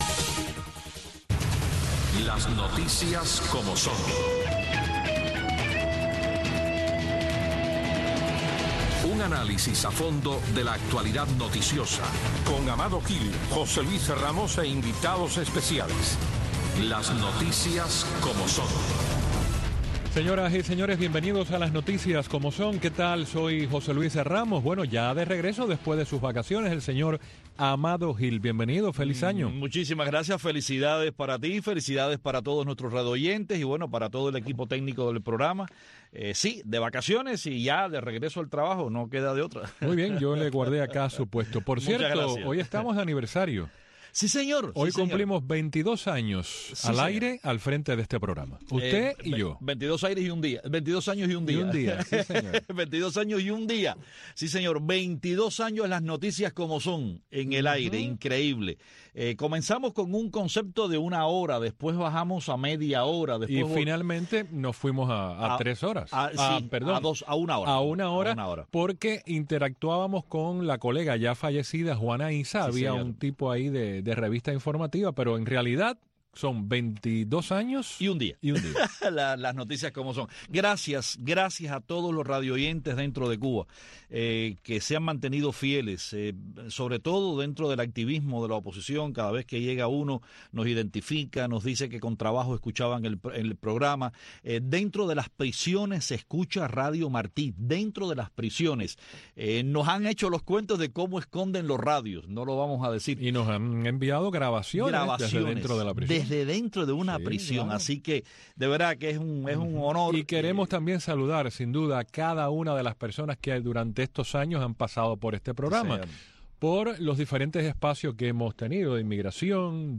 También te invitamos a escuchar un debate entre dos reconocidos opositores cubanos que tienen puntos de vista diferentes en cuanto a la suspensión de vuelos Chárters al interior de Cuba.